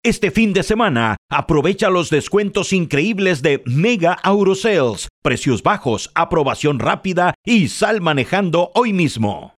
Spanish (Mexican)
Adult (30-50) | Older Sound (50+)
0302Comercial_de_Radio.mp3